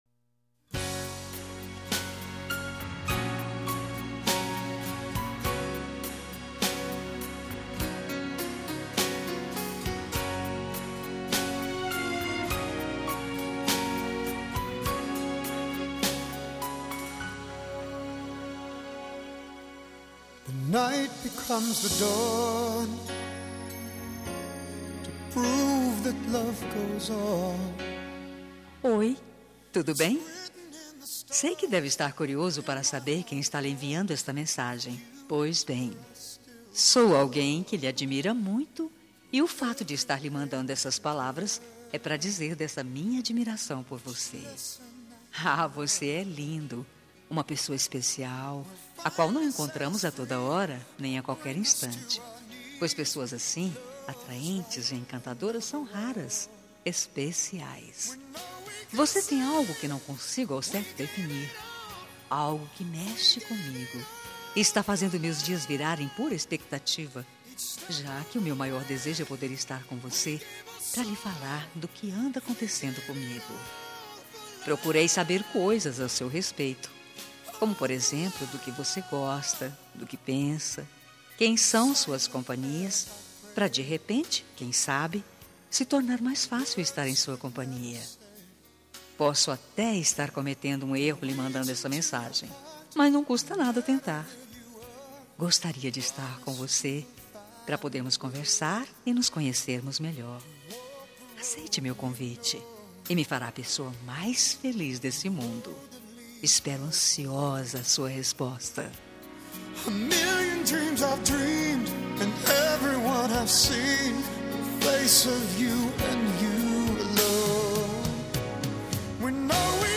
Telemensagem de Conquista – Voz Feminina – Cód: 140114